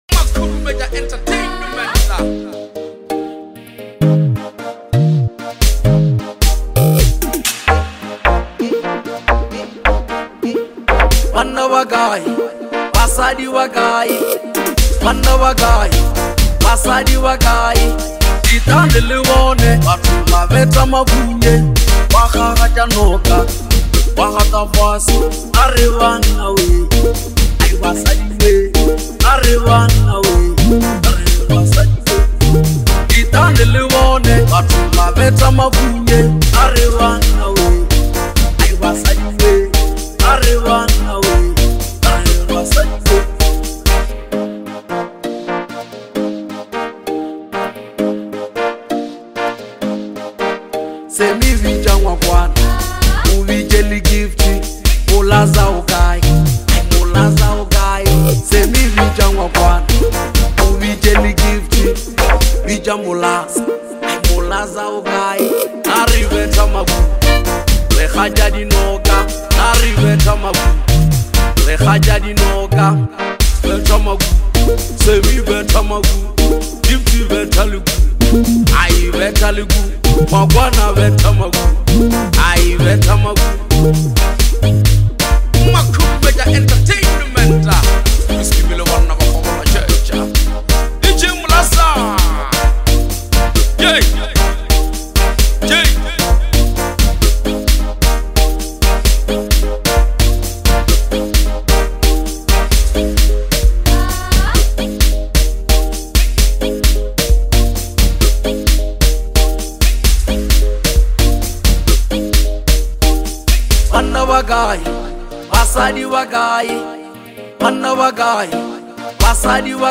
is a vibrant Afro Beat single